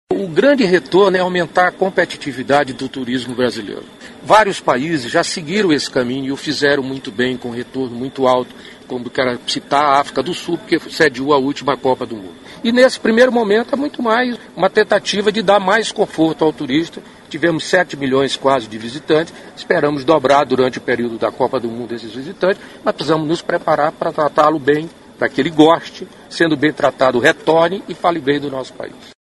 aqui e ouça declaração do ministro Gastão Vieira sobre a importância do investimento em parques nacionais.